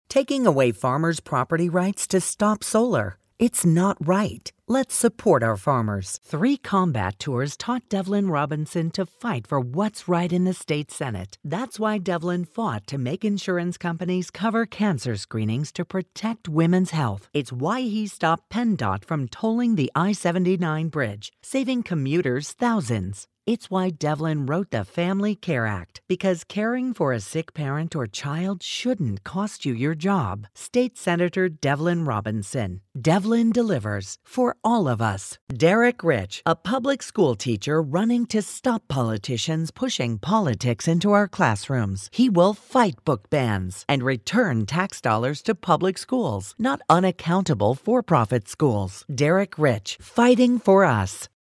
Female
Adult (30-50)
Political Spots
Authoritative, Strong